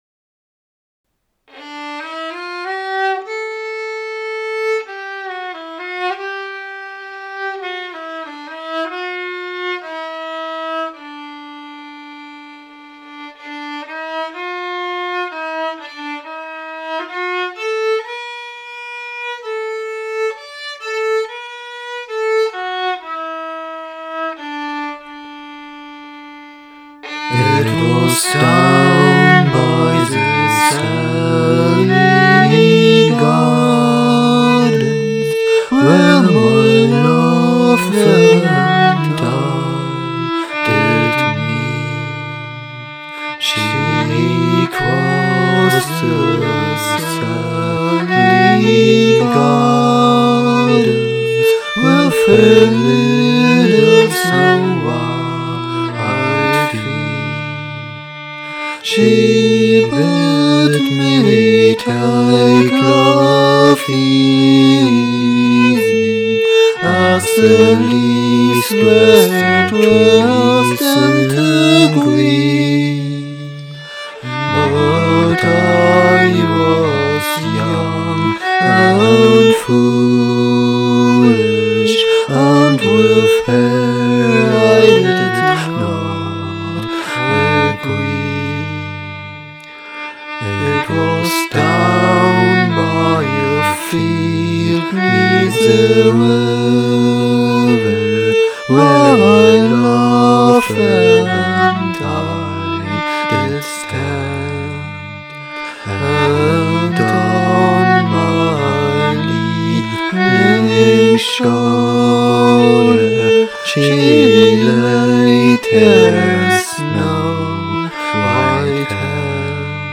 Melodie: Irishes Volkslied